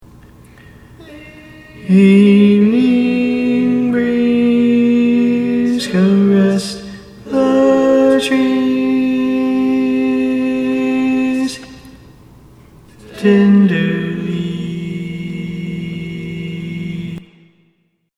Key written in: E♭ Major
How many parts: 5
Type: Other male